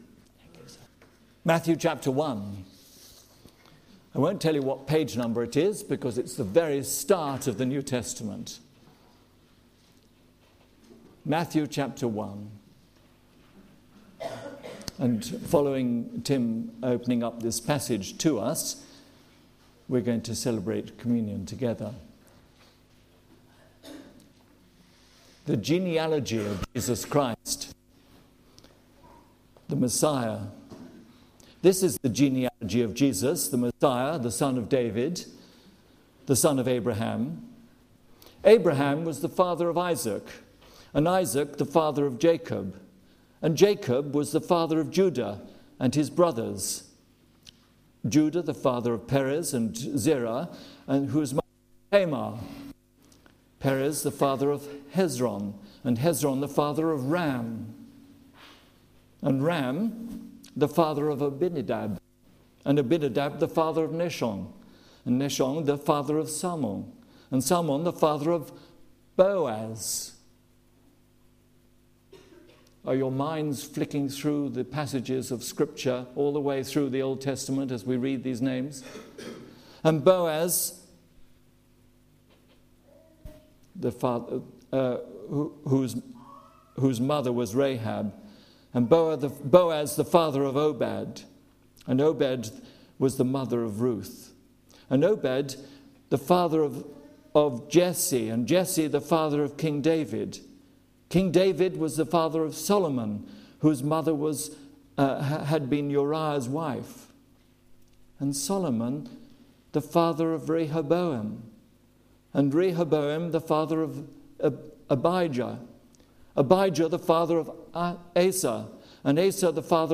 Sunday Service
Series: Women of the Bible Theme: Women in Matthew's Genealogy Sermon